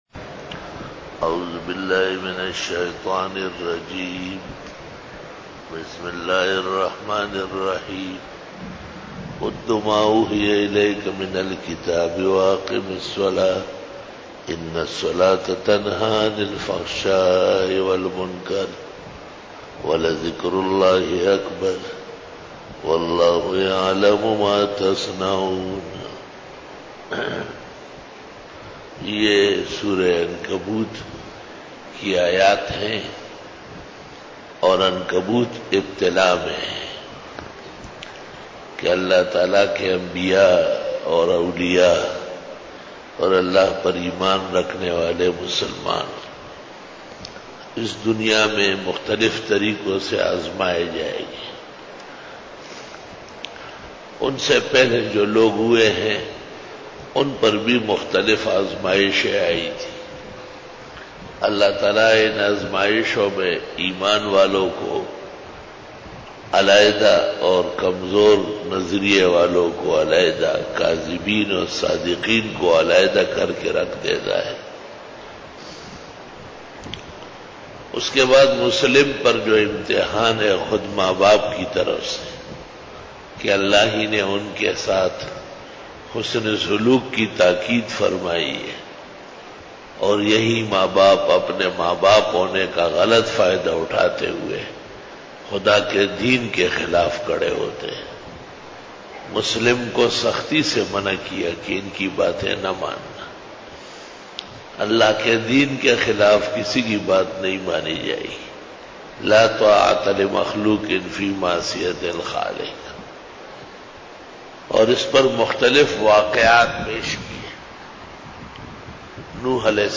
سورۃ العنکبوت رکوع-05 Bayan